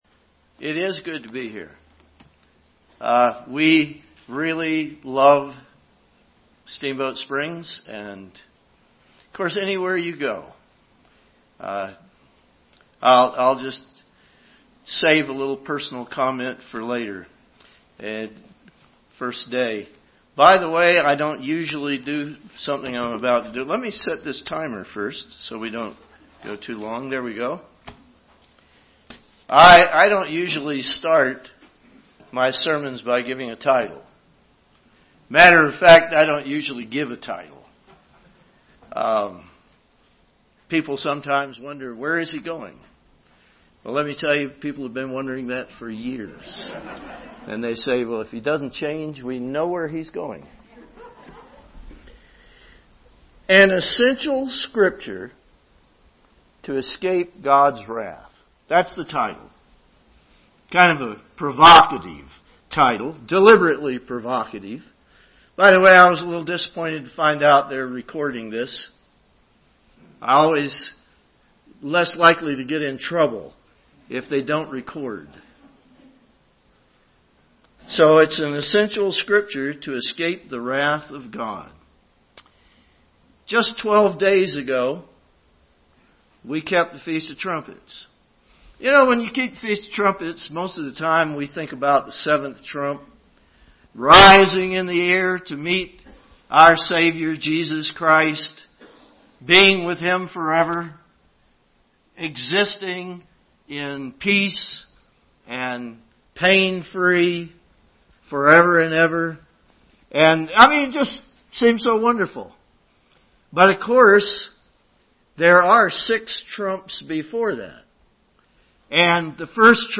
This sermon was given at the Steamboat Springs, Colorado 2015 Feast site.